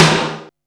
M SNARE 4.wav